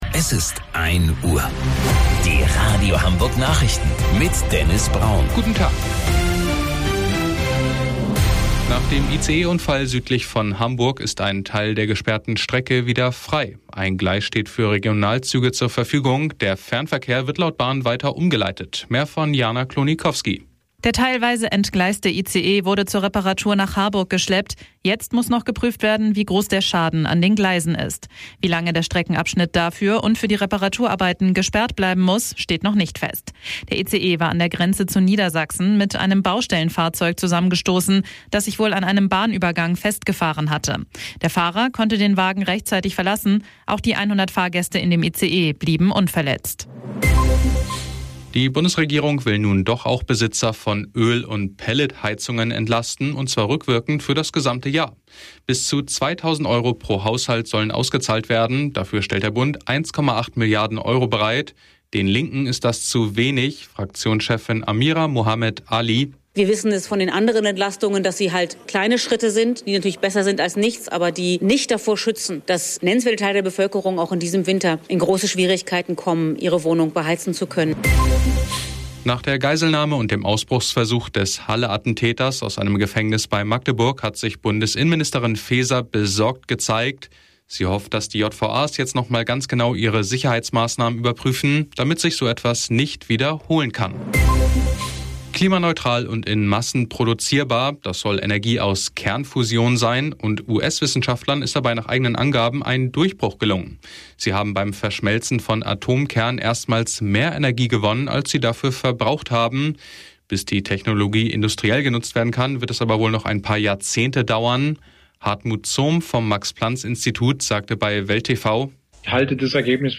Radio Hamburg Nachrichten vom 09.09.2022 um 03 Uhr - 09.09.2022